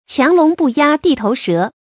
强龙不压地头蛇 qiáng lóng bù yā dì tóu shé
强龙不压地头蛇发音